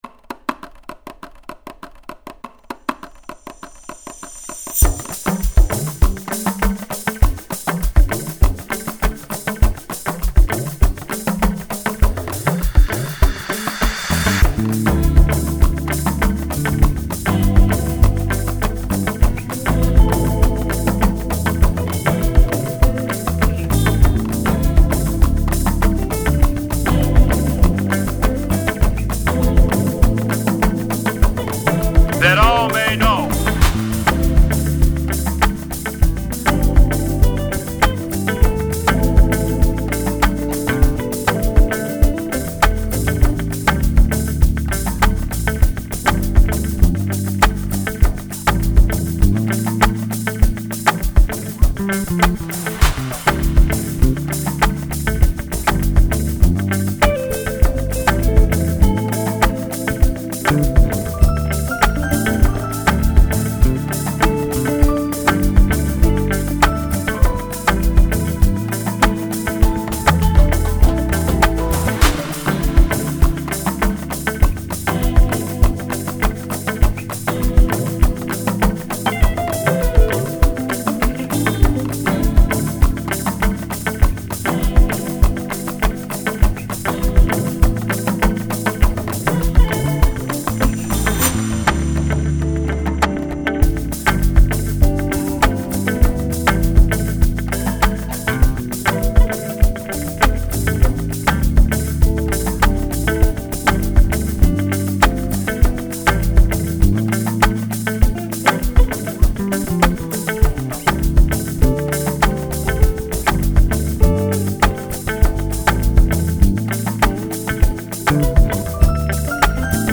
Category: Afro Apala